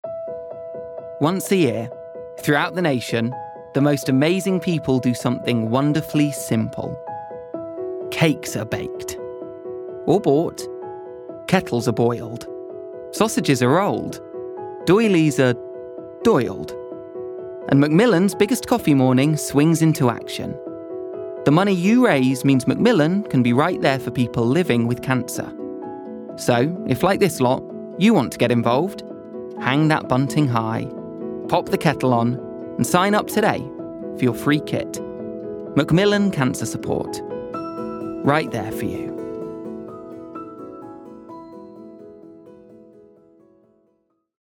Macmillan - Calm, Warm, Grounded